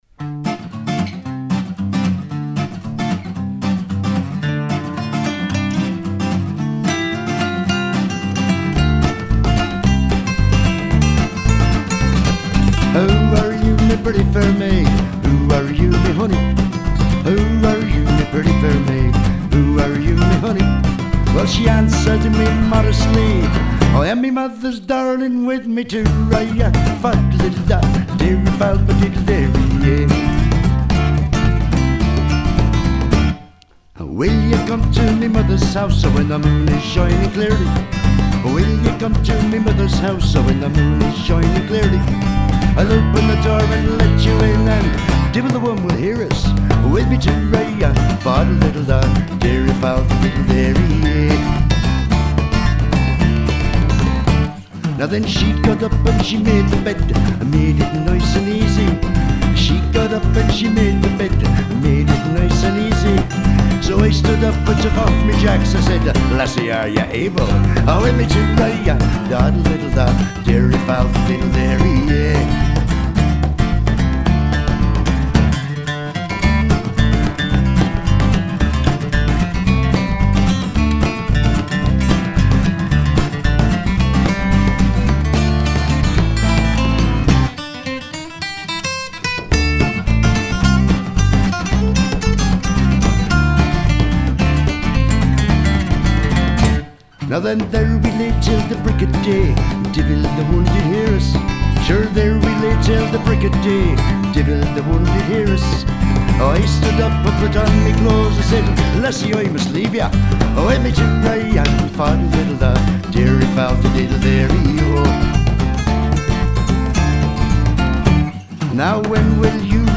(trad.)
vocal, bodhran
guitar
guitar, bass, backing vocal